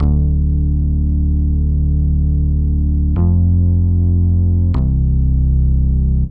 bass.wav